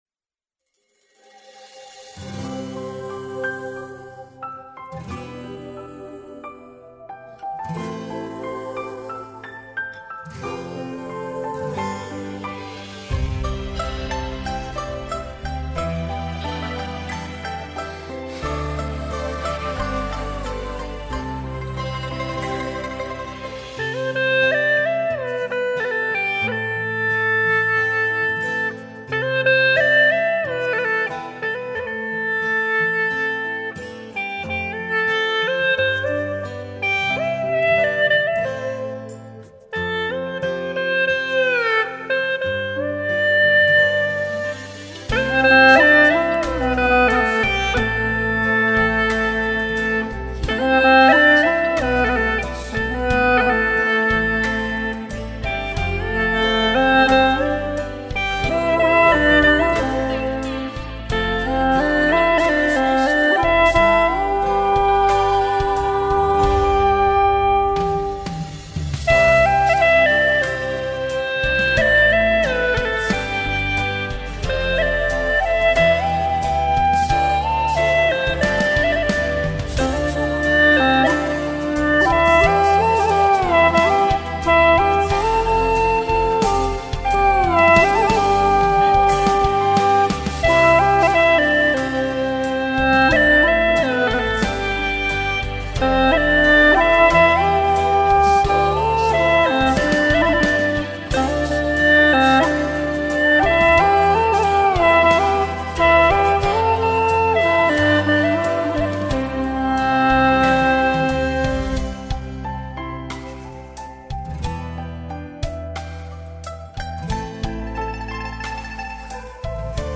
调式 : C 曲类 : 流行